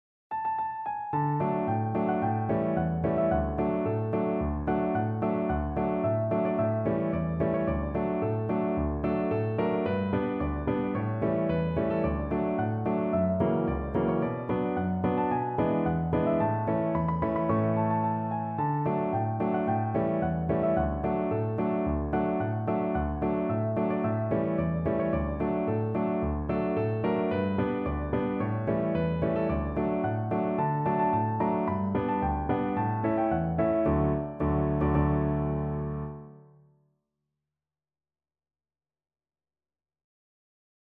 Вот наигранный по памяти фрагмент